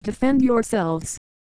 Worms speechbanks
takecover.wav